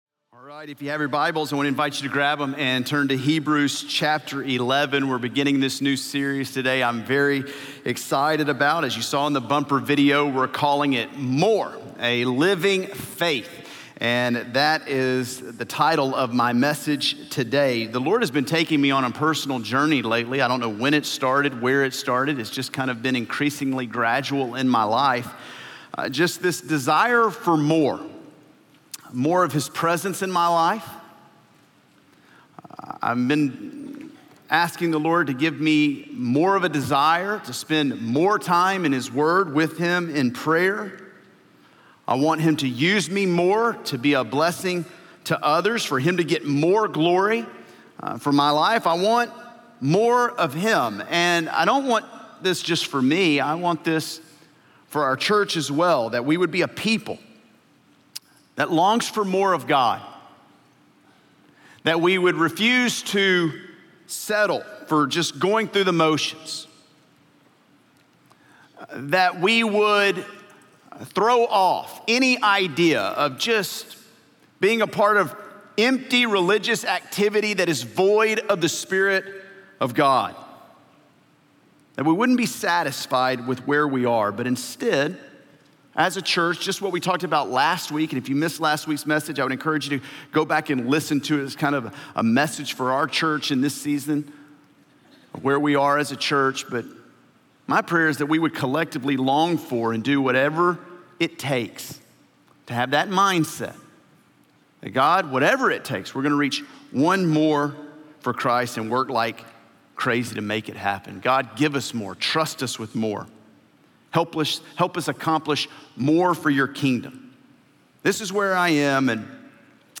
A message from the series "The Thread - JV."